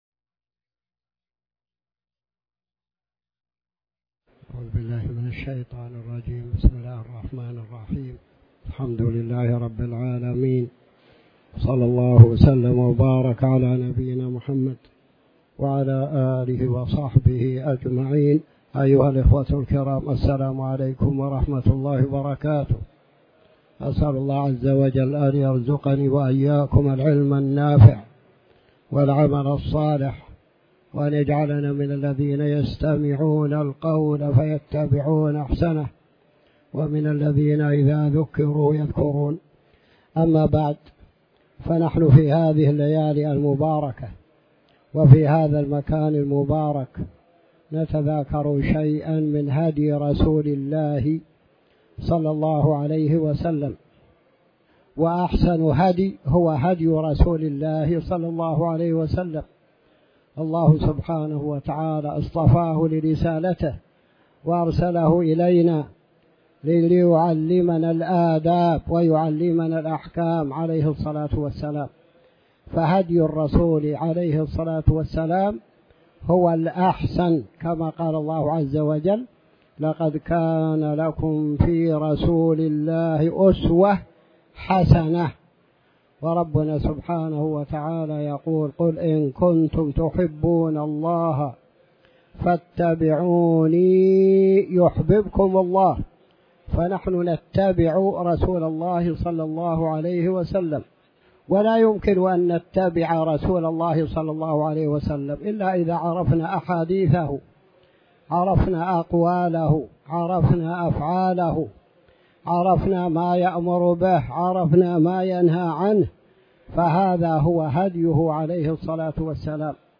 تاريخ النشر ١٢ محرم ١٤٤٠ هـ المكان: المسجد الحرام الشيخ